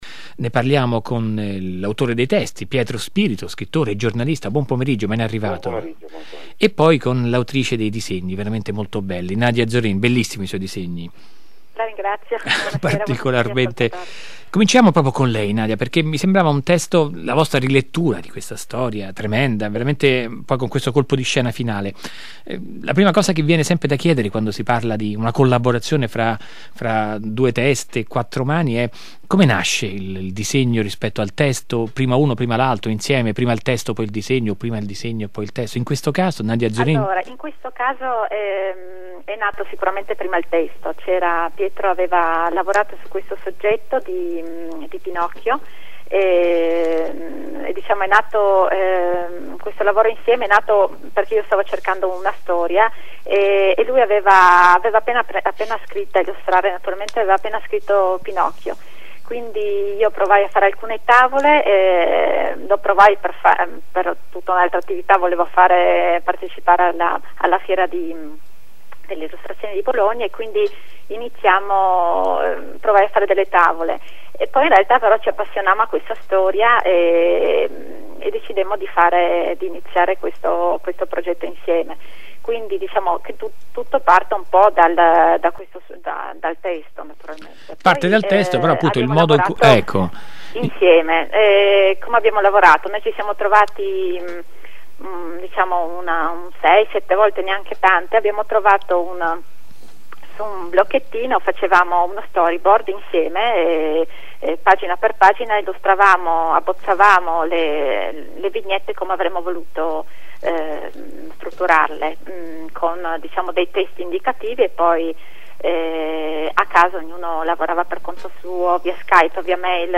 Intervista agli autori su Rai Radio 3 Fahrenheit (14 settembre 2011)